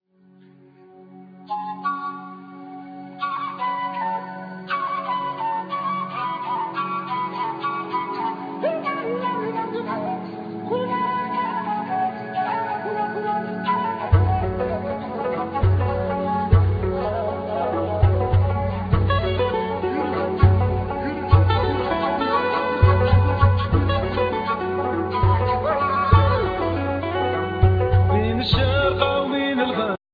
Lead Vocal,Drums,Percussion,Gumbri
Banjo,Mandola,Background vocals
Soprano & Tenor Saxophone